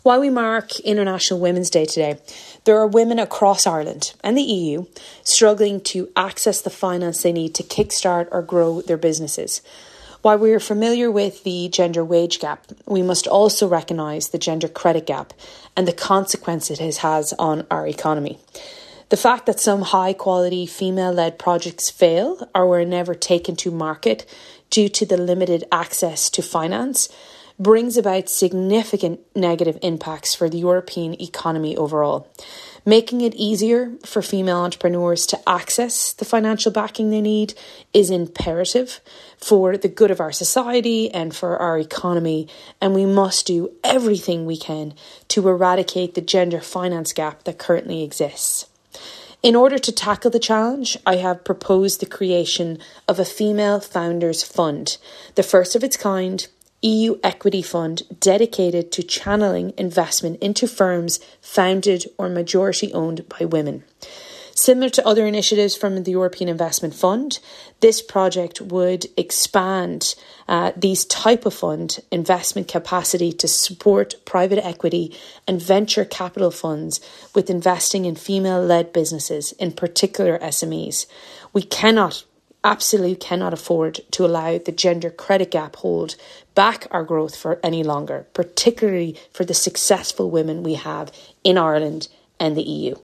MEP Walsh says while there is awareness around the gender pay gap, there must be more said on the gender credit gap: